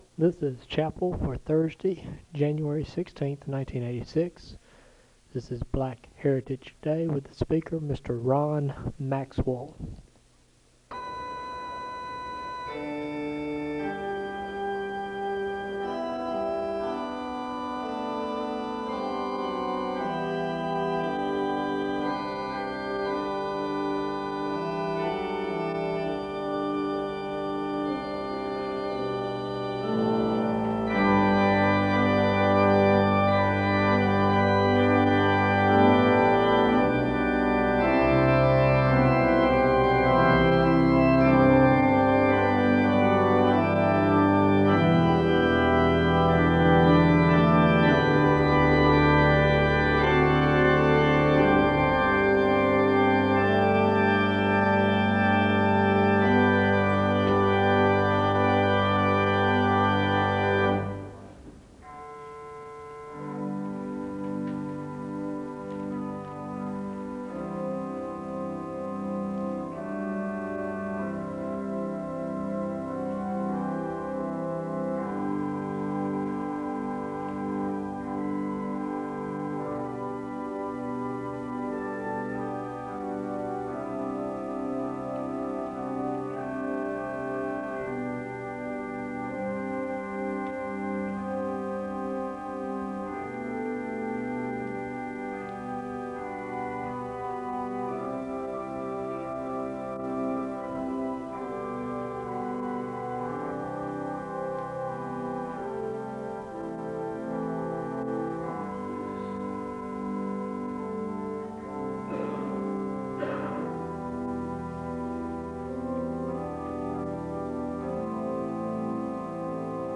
The service begins with organ music (0:00-5:58). There is a Scripture reading and a moment of prayer (5:59-7:28). Scripture is read from Matthew 7:1-8 (7:29-8:26).
The service closes in a moment of prayer (22:22-22:37).